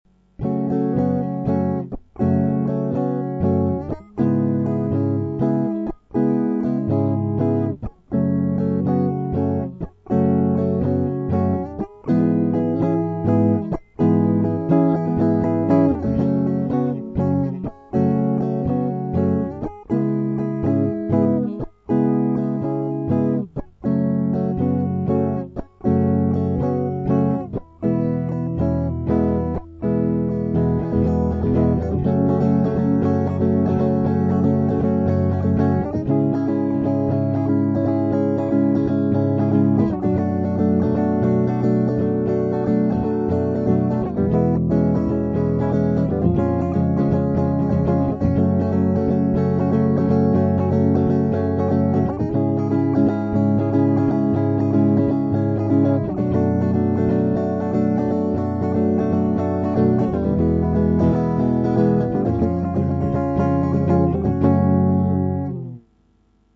В куплетах каждый аккорд раскладываем так:
mp3 - куплет и припев